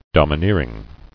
[dom·i·neer·ing]